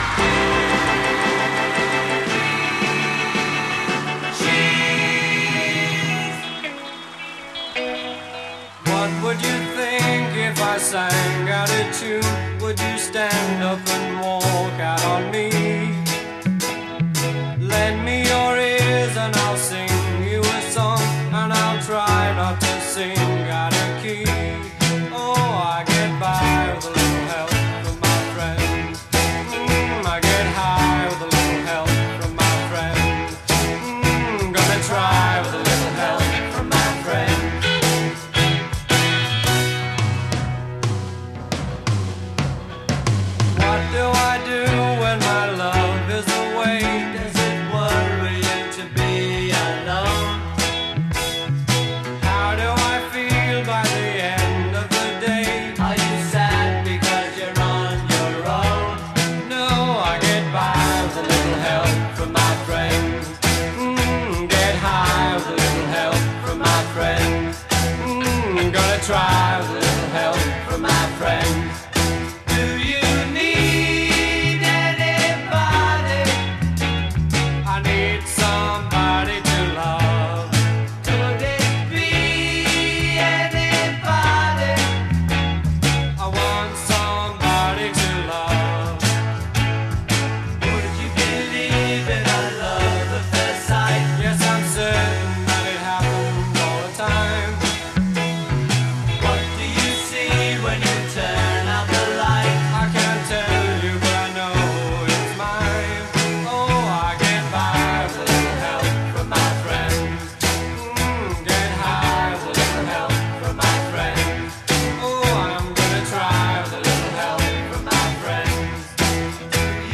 chorus   1:13 8 2-part chorus questions; singer responds. e